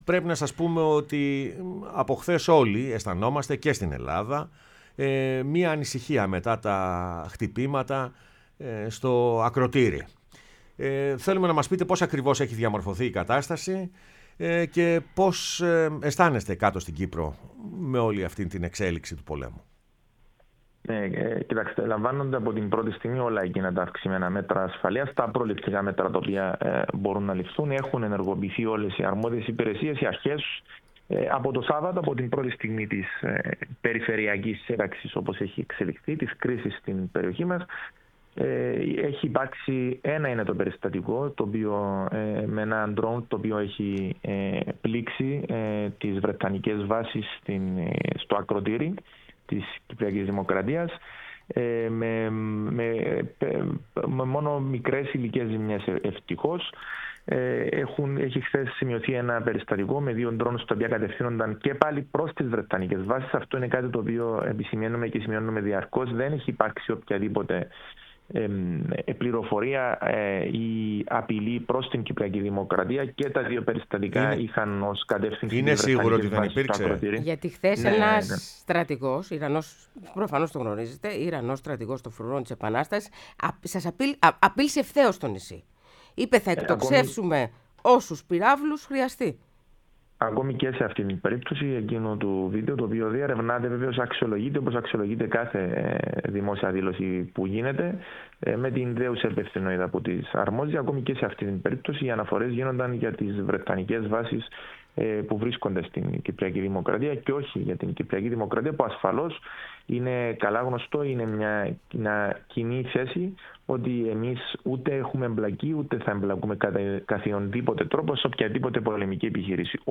O Κωνσταντίνος Λετυμπιώτης, Κυβερνητικός Εκπρόσωπος  Κύπρου μίλησε στην εκπομπή “Πρωινές Διαδρομές”